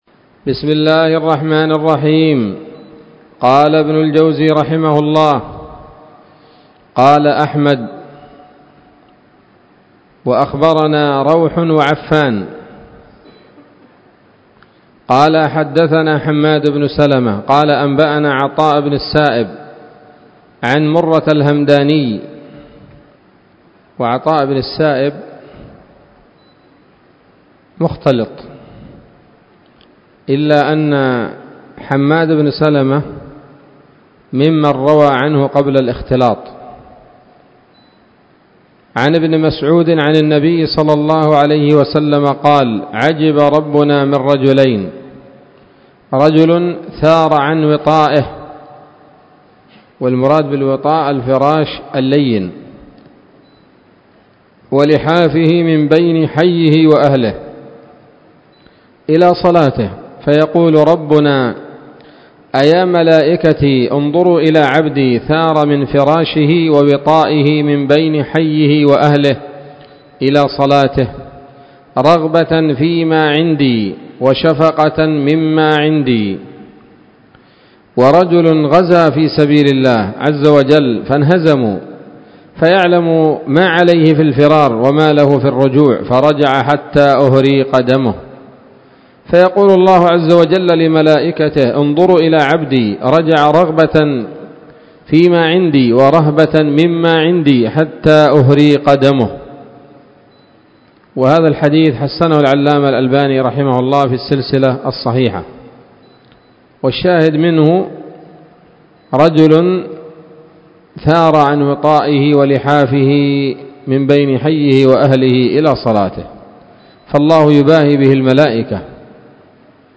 الدرس الرابع من كتاب "قيام الليل" لابن الجوزي رحمه الله تعالى